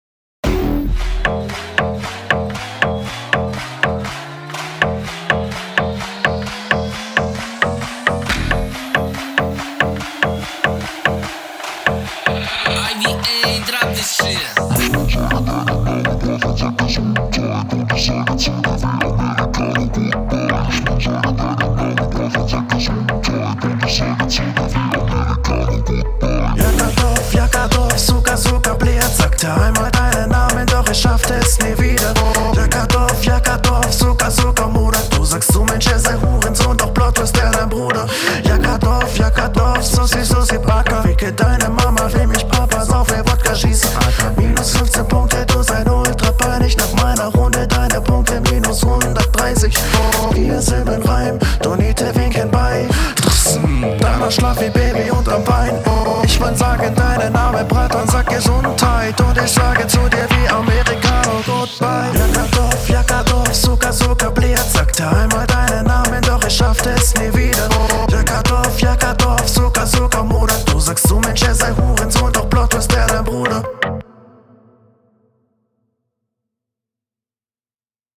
Flow: stimme und flow sind echt gut auch wenn da ein bisschen druck in der …